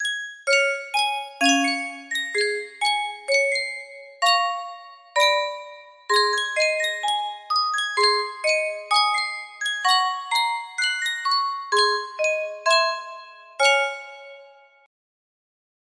Sankyo Music Box - If You Knew Susie F- music box melody
Full range 60